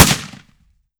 7Mag Bolt Action Rifle - Gunshot B 002.wav